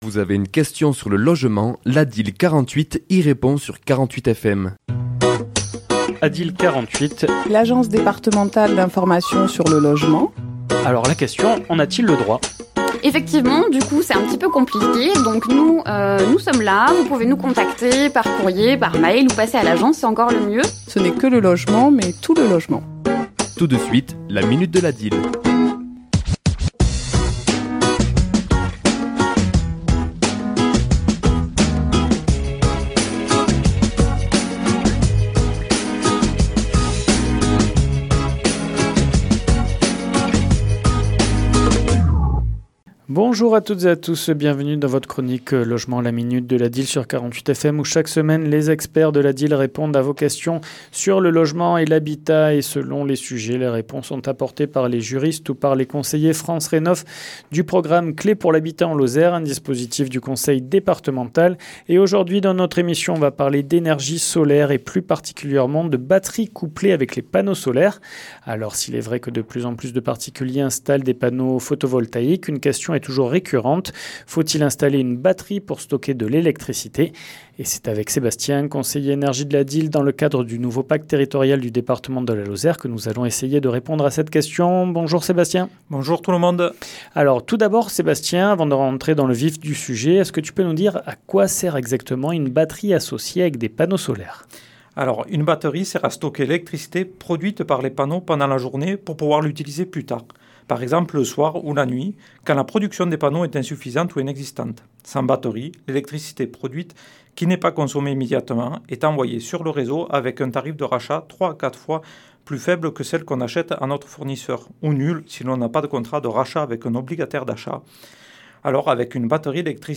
Chronique diffusée le mardi 14 avril à 11h et 17h10